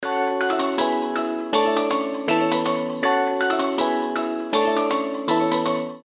• Качество: 320, Stereo
мелодичные
без слов
рождественские
Короткая мелодичная смс-ка с рождественским настроением